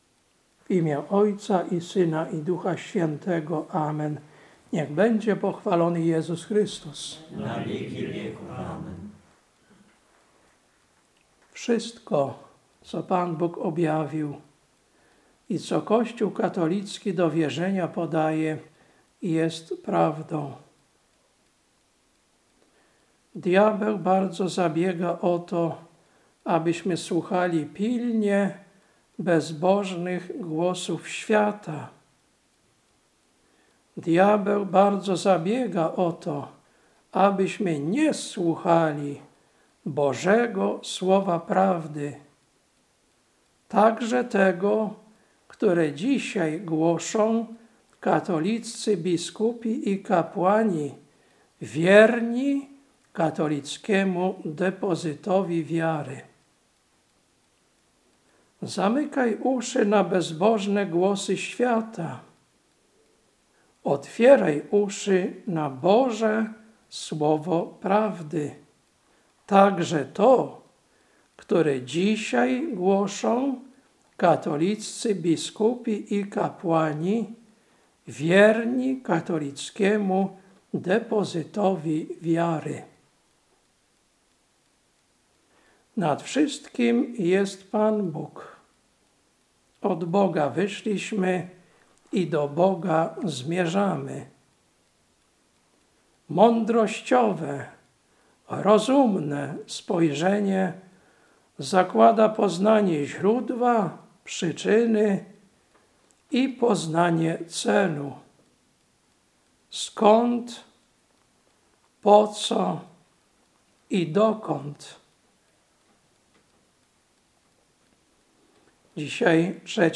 Kazanie na III Niedzielę Wielkiego Postu, 8.03.2026 Lekcja: Ef 5, 1-9 Ewangelia: Łk 11, 14-28